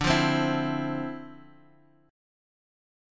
Dm9 chord